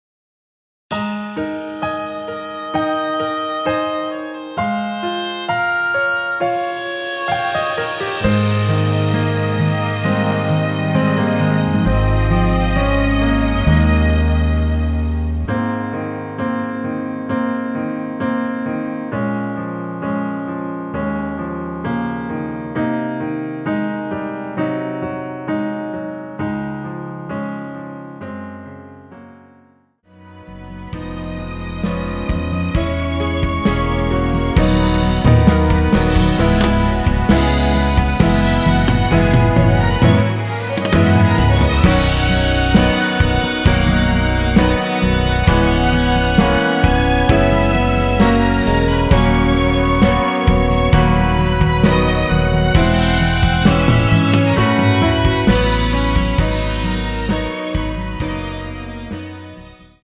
원키에서(-2)내린 1절 + 후렴으로 편곡한 MR 입니다.(미리듣기및 가사 참조)
Ab
앞부분30초, 뒷부분30초씩 편집해서 올려 드리고 있습니다.
중간에 음이 끈어지고 다시 나오는 이유는